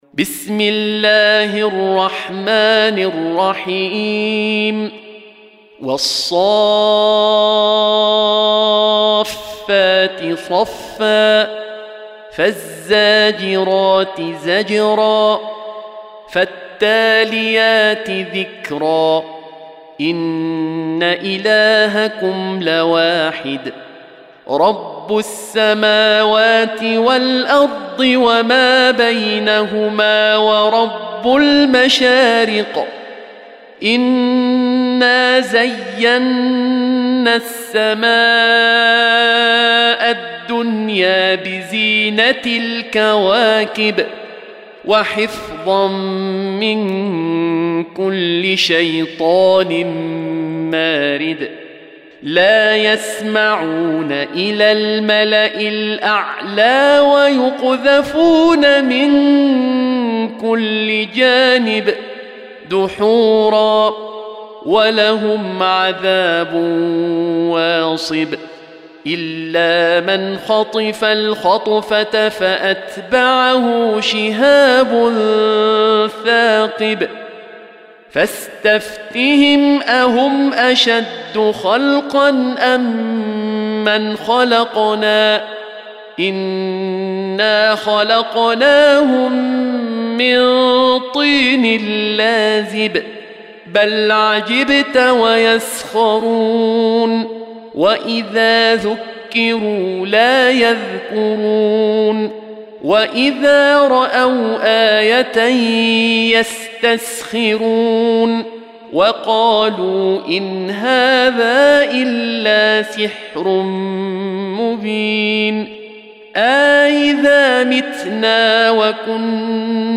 Surah Repeating تكرار السورة Download Surah حمّل السورة Reciting Murattalah Audio for 37. Surah As-S�ff�t سورة الصافات N.B *Surah Includes Al-Basmalah Reciters Sequents تتابع التلاوات Reciters Repeats تكرار التلاوات